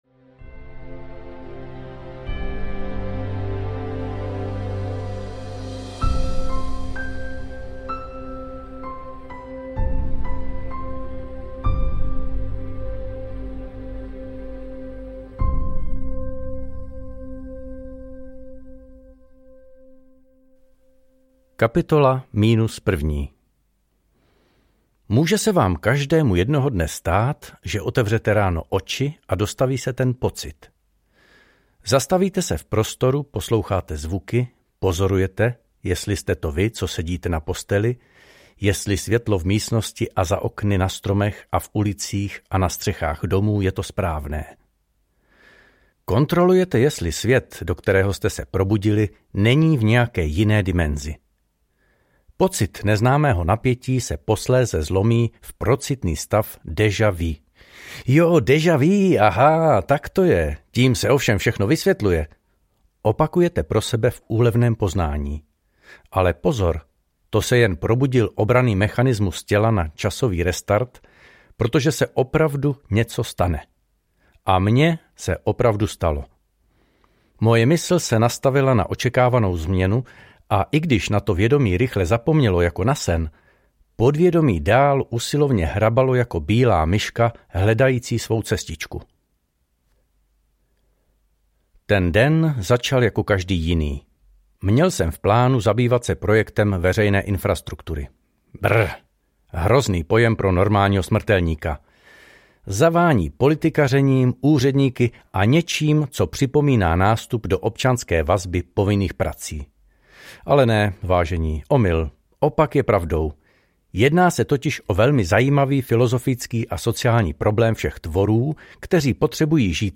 Mentogriti audiokniha
Ukázka z knihy